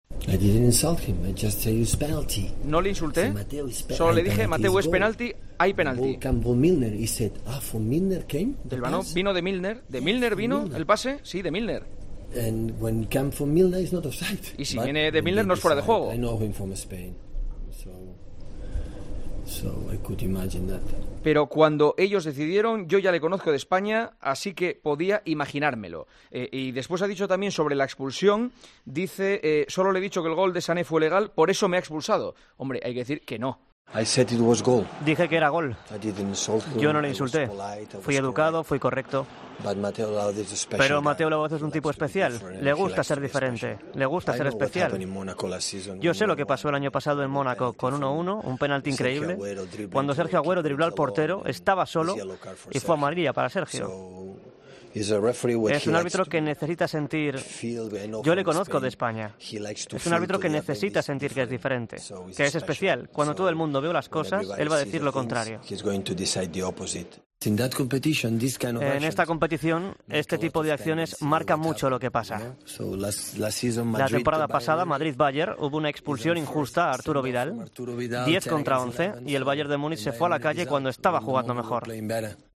"A Mateu Lahoz le gusta ser especial", dijo Pep Guardiola en BT Sport con respecto a la actuación del árbitro español, que le expulsó al descanso del Manchester City - Liverpool.